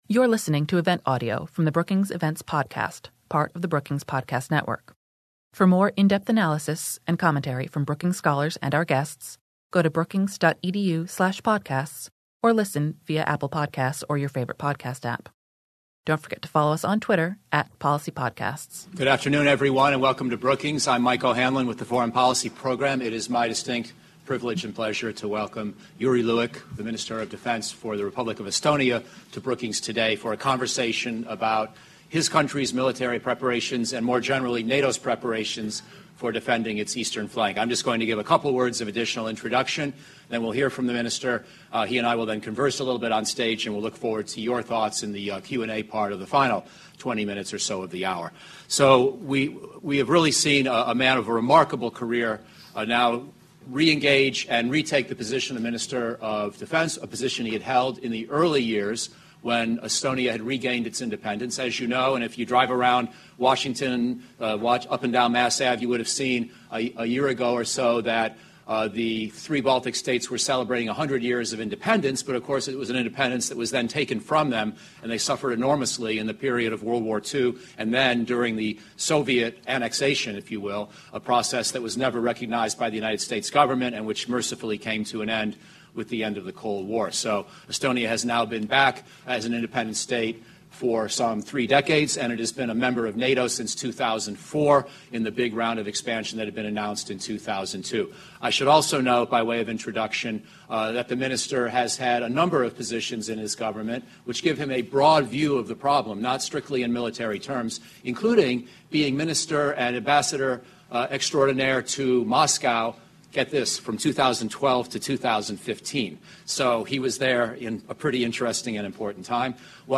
On March 2, the Brookings Institution hosted Jüri Luik, Estonia’s minister of defense, for a conversation on Russia, the security environment in Europe, and the challenges facing NATO in light of renewed great power competition.
Discussion